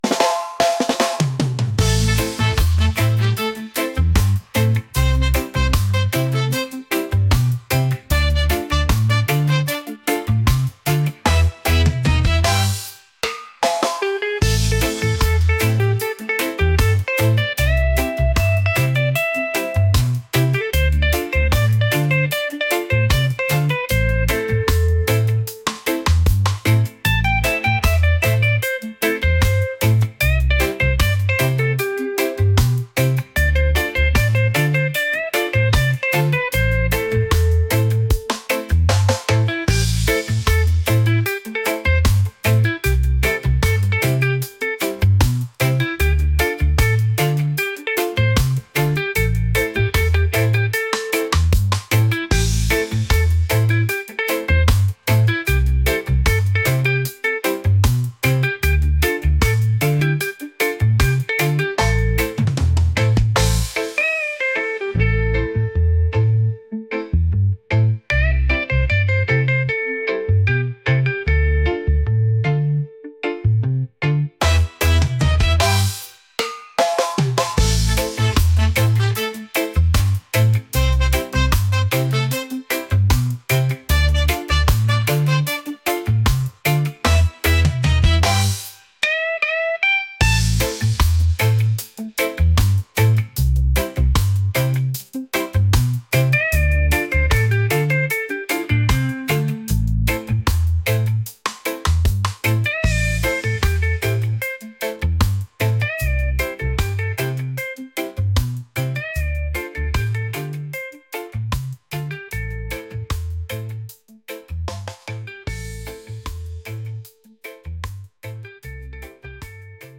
island | groovy | reggae